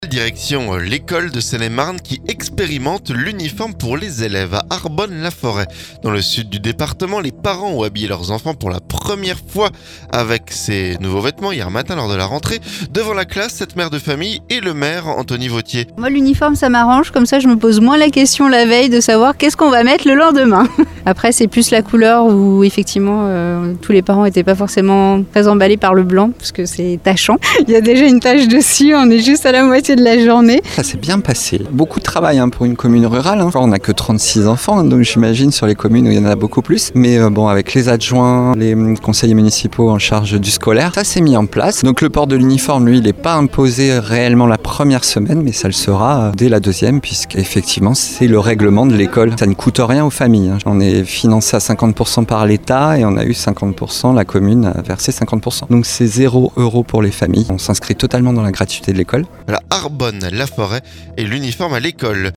A Arbonne-la-forêt, dans le sud du département, les parents ont habillé leurs enfants pour la première fois hier matin lors de la rentrée. Devant la classe, une mère de famille et le maire Anthony Vautier.